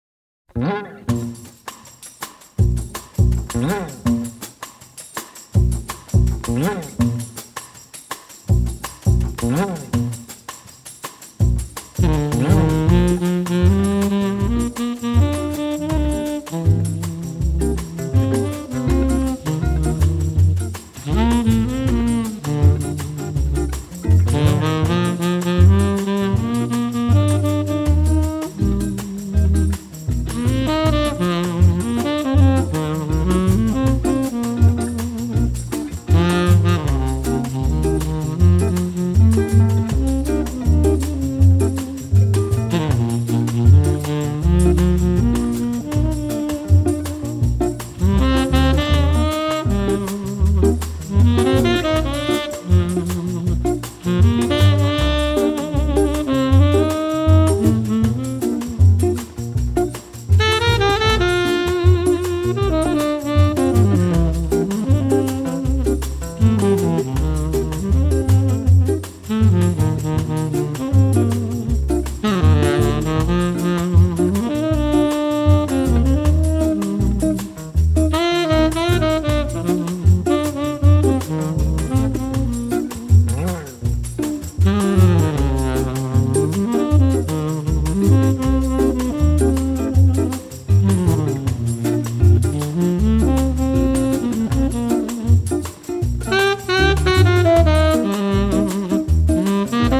★ 美麗質樸、輕鬆優雅的薩克斯風語調，集柔和、浪漫、慵懶、甜美與性感等於一身。
tenor sax
guitar
bass
drum
chekere.